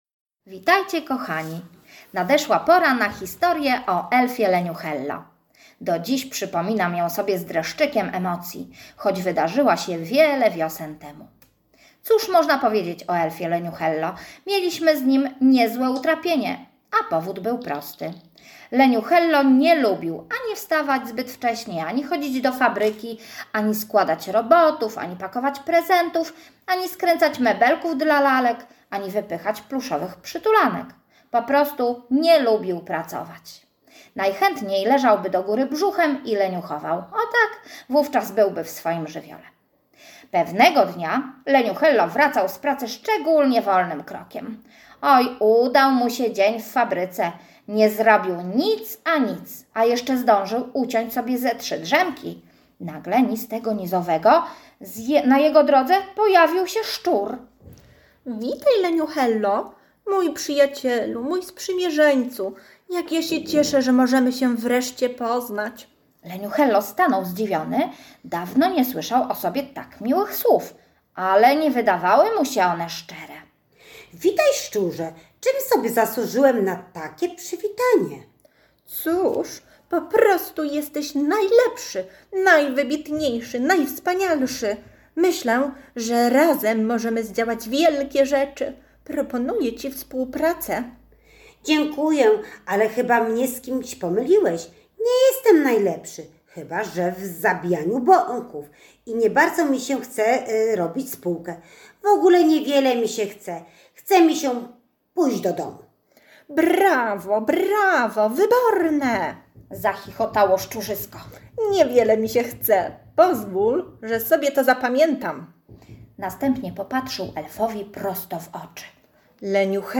bajka-elf.mp3